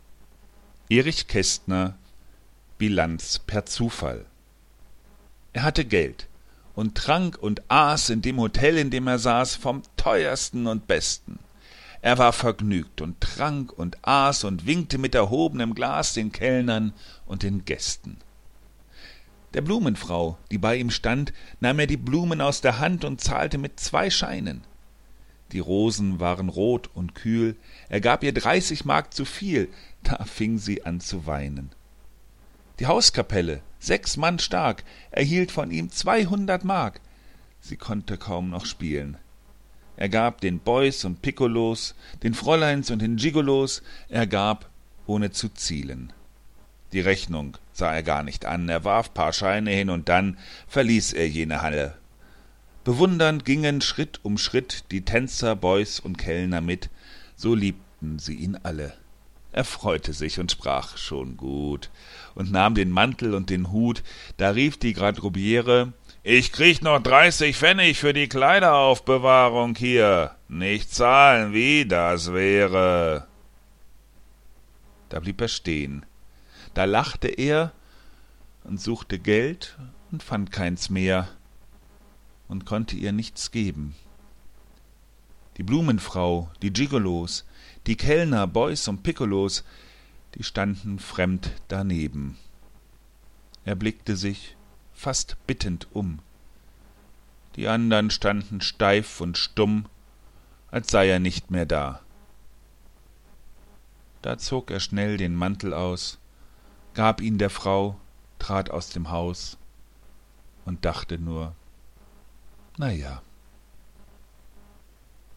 Gedichte, gesprochen